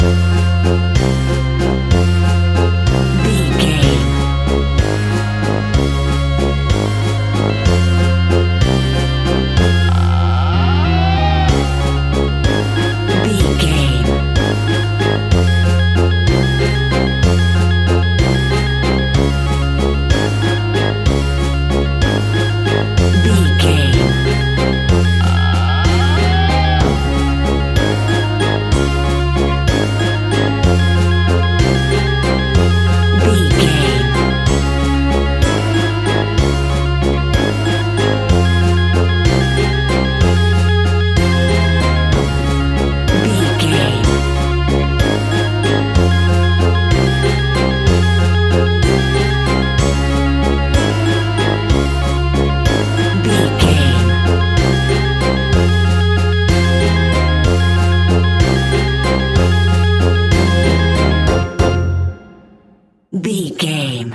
Aeolian/Minor
ominous
dark
eerie
brass
electric organ
drums
synthesiser
strings
spooky
horror music